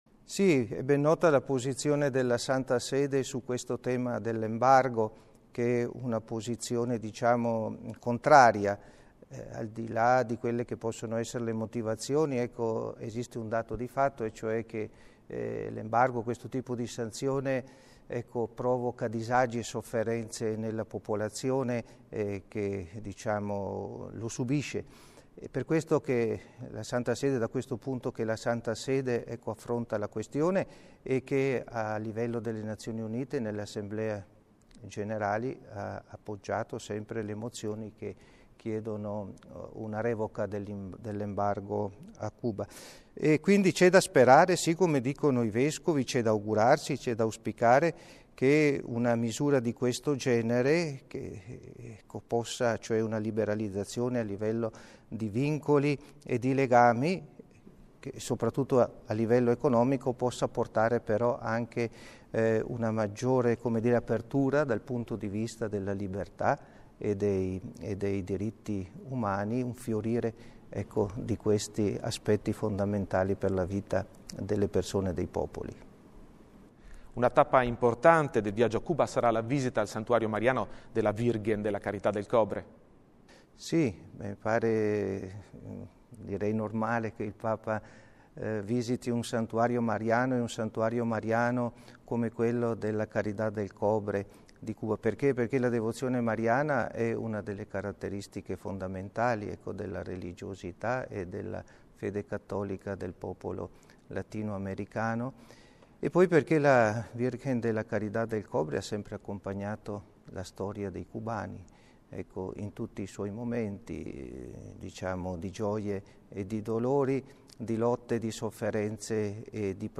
ha intervistato il segretario di Stato vaticano, cardinale Pietro Parolin